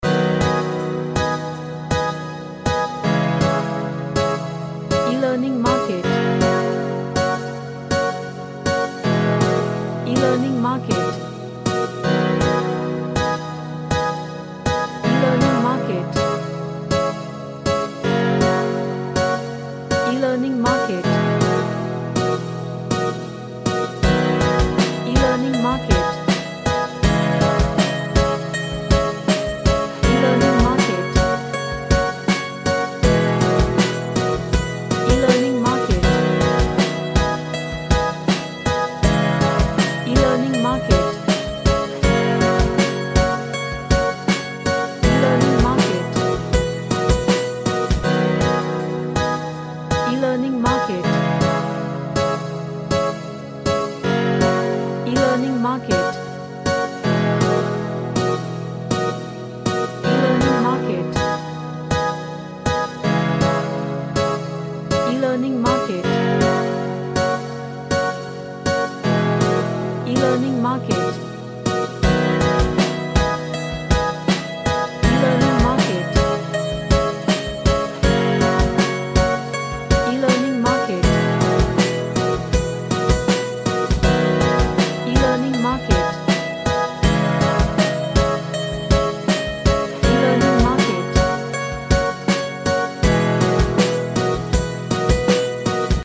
A funky reggae track with lots groove to it.
Happy